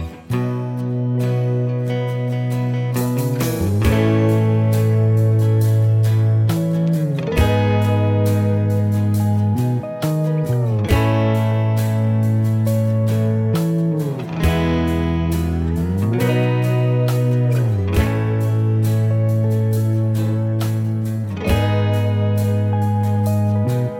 Cut Down Country (Male) 4:09 Buy £1.50